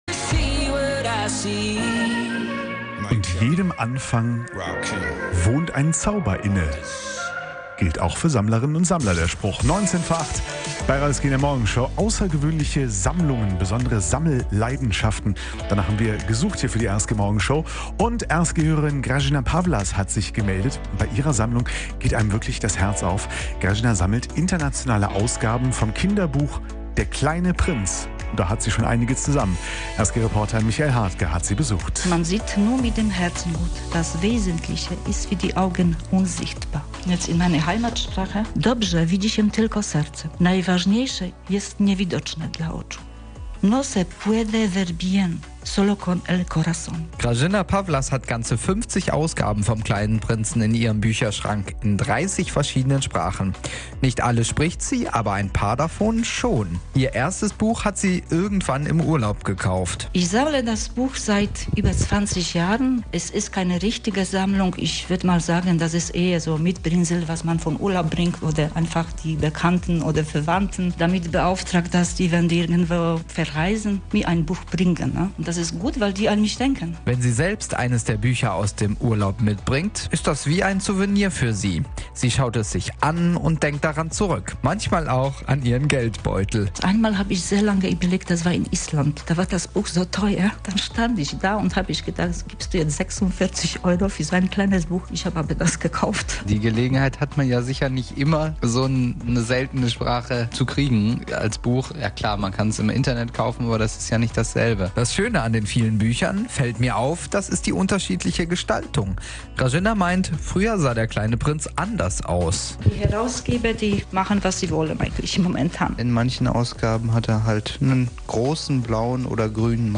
Ein paar Sammler aus Solingen und Remscheid hat unser Reporter besucht...